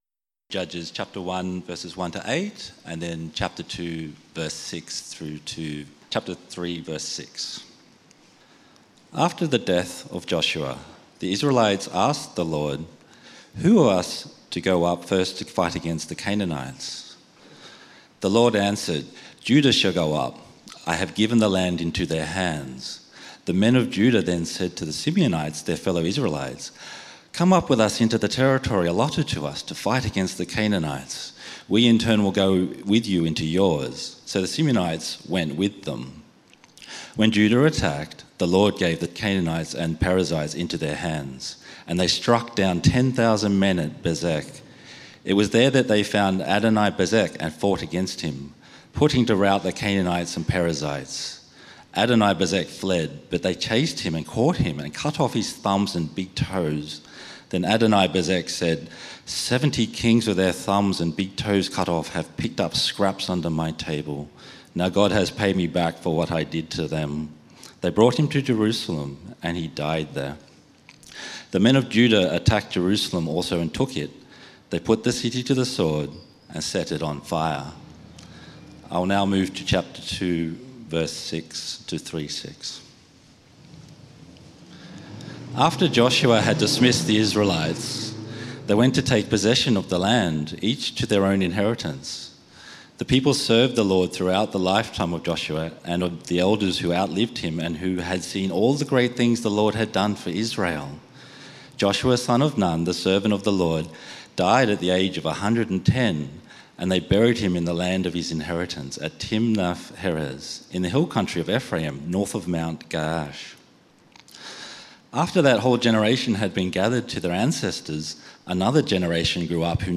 The Israelites Did Evil in the Eyes of the LORD SERMON OUTLINE Share this page